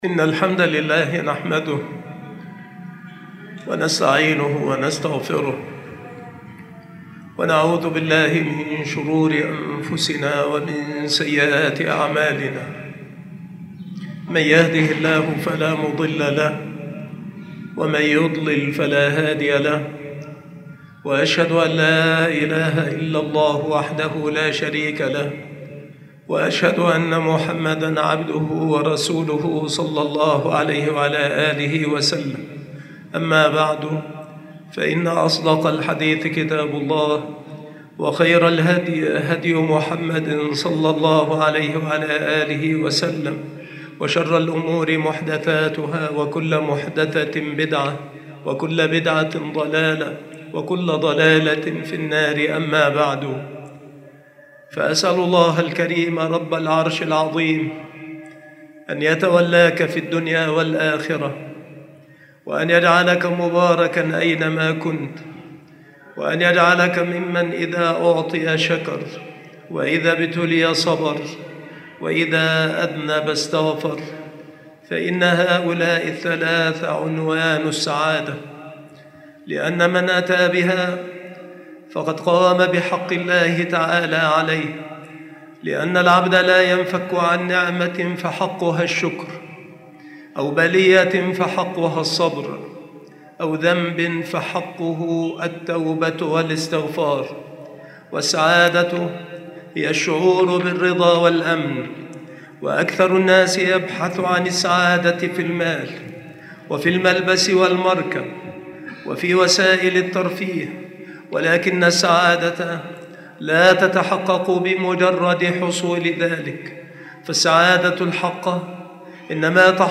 المحاضرة
مكان إلقاء هذه المحاضرة بالمسجد الشرقي - سبك الأحد - أشمون - محافظة المنوفية - مصر